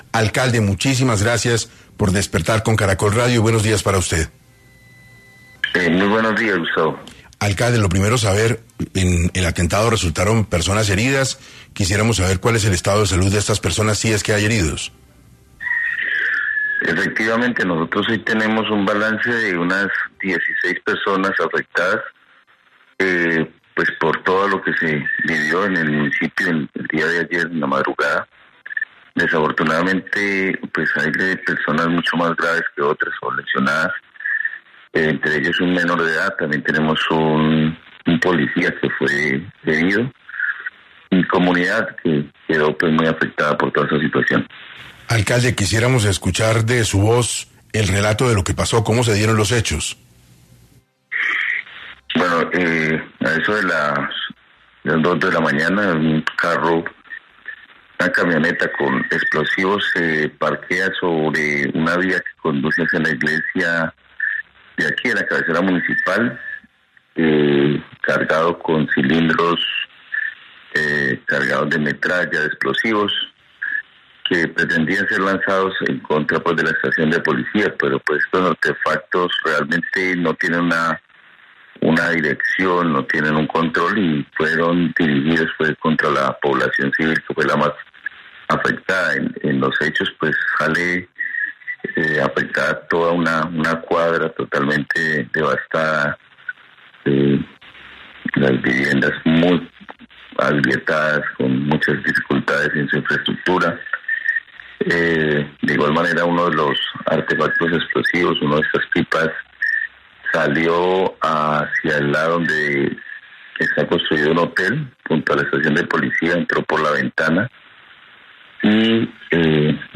En entrevista con 6AM de Caracol Radio, el alcalde César Cerón, confirmó el balance de unas 16 personas afectadas por todo lo que se vivió en el municipio, desafortunadamente, hay personas mucho más graves que otras, lesionadas, entre ellos un menor de edad.